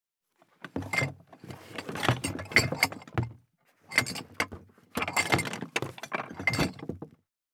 184,地震,引っ越し,荷物運び,段ボール箱の中身,部署移動,ドタドタ,バリバリ,カチャン,ギシギシ,ゴン,ドカン,ズルズル,タン,パタン,ドシン,
効果音荷物運び